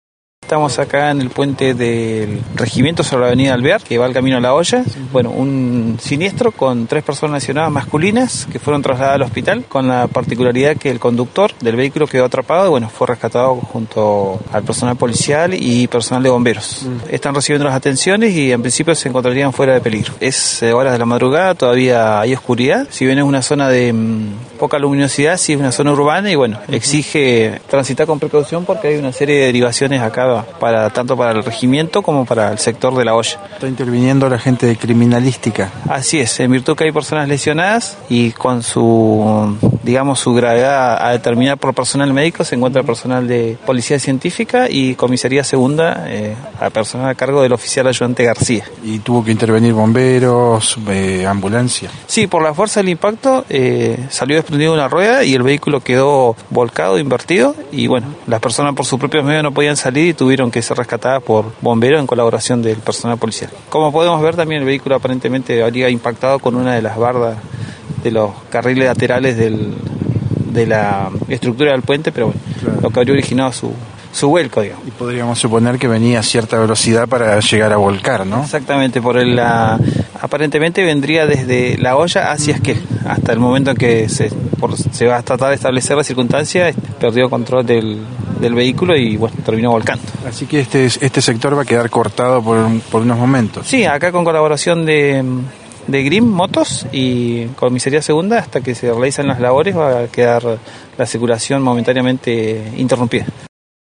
conversó con Noticias de Esquel, único medio en el lugar.